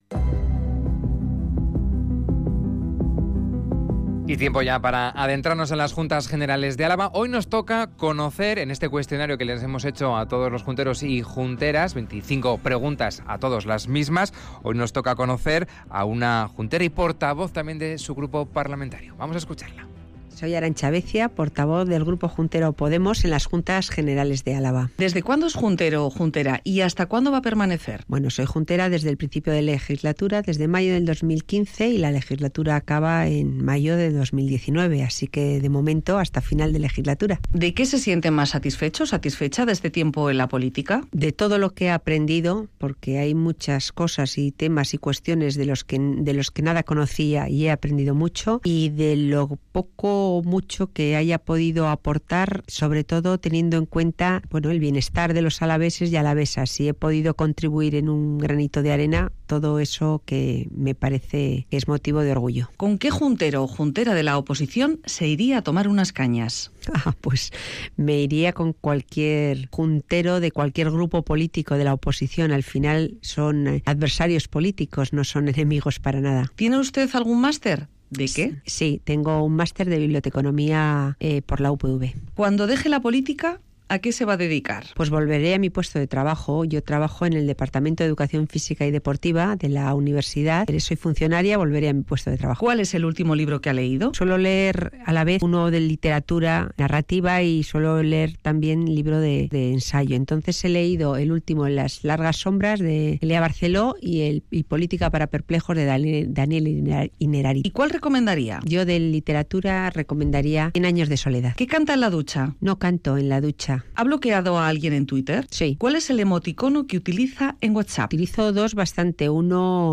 Audio: En nuestro espacio 'El Purgatorio', descubrimos a la procuradora y portavoz del grupo juntero de Podemos, Arantxa Abecia. Un test con veinticinco preguntas personales.